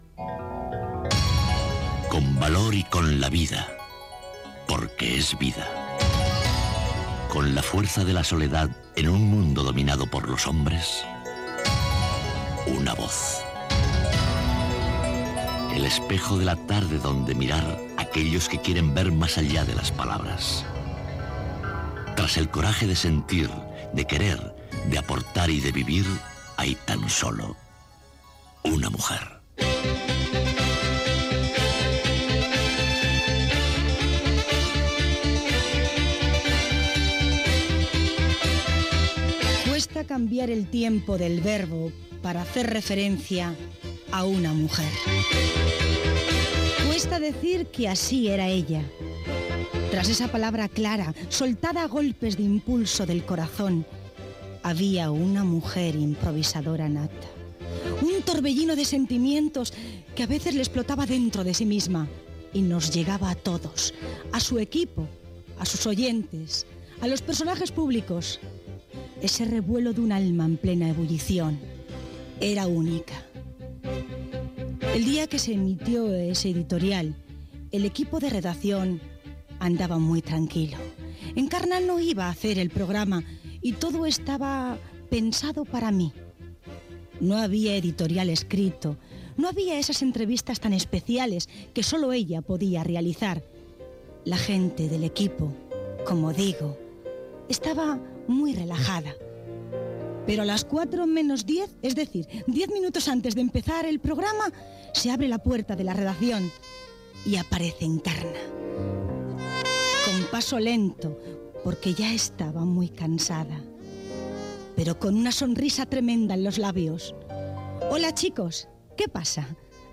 Repàs a la seva biografia professional Gènere radiofònic Entreteniment